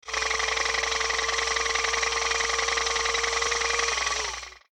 Golf_Moving_Barrier.ogg